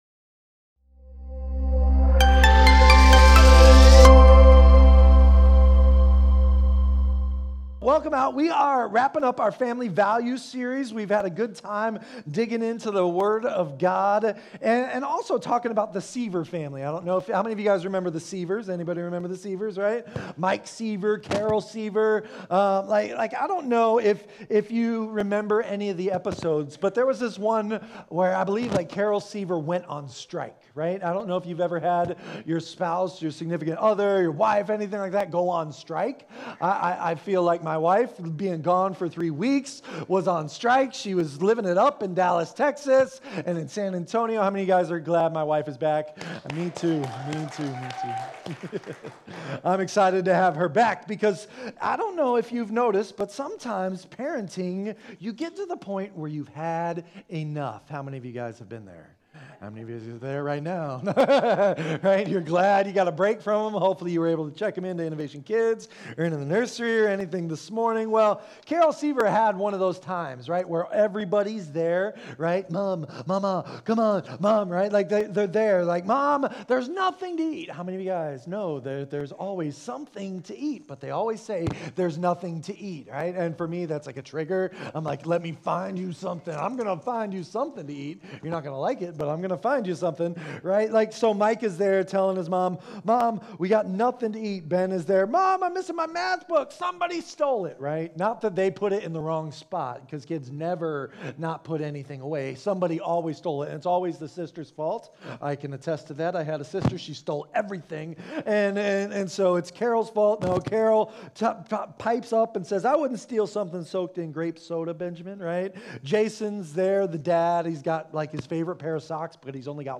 In the fourth sermon of the "Family Values" series, titled "Strength & Community," we explore the powerful example of Moses, a leader at his breaking point.